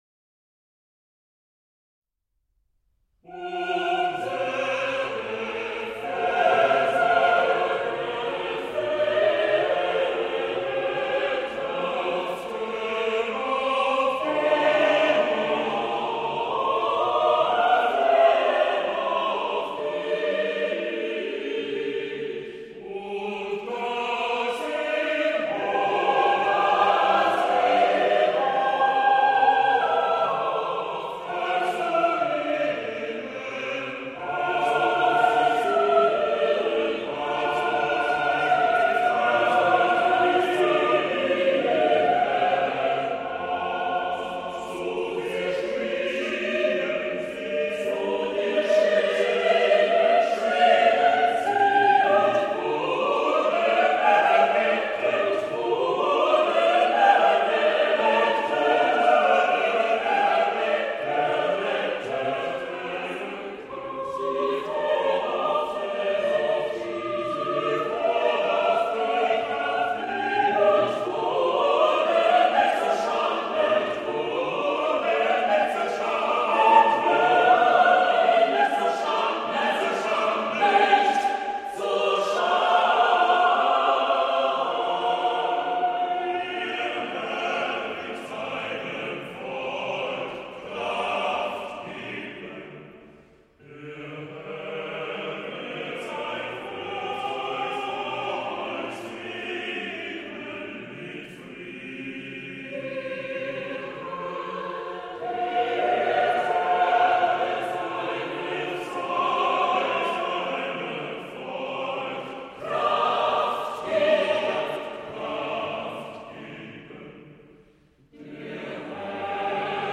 Choir
A musical ensemble of singers.
for 8 part chorus a cappella